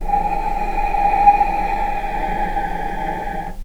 vc-G#5-pp.AIF